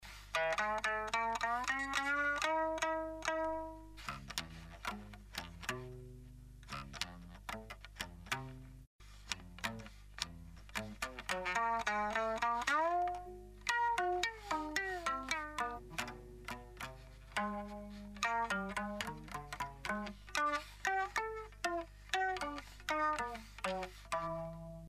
"Jicera" intrument de musique silencieux destiné a produire des ondes traitées par l'ordinateur :
les instruments sont couplés a des samplers....ect ect, puis l emission se fait via "shoutcast" (emission radio web)
quelques sonorités de la jicera :